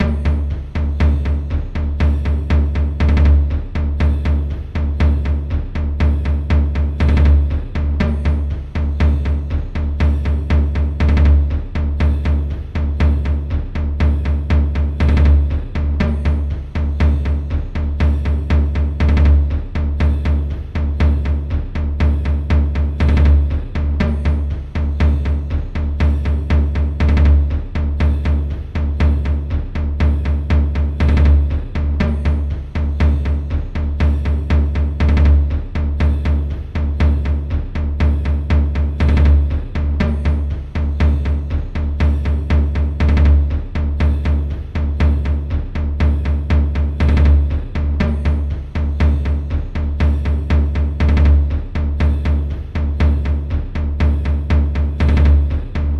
描述：节奏，行进的低音，以奇怪的方式门控。
记录在Cubase中。